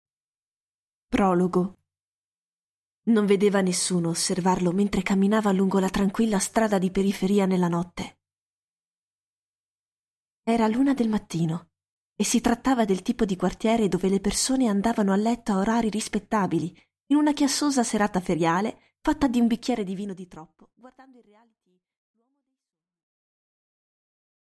Аудиокнига Se lei sapesse | Библиотека аудиокниг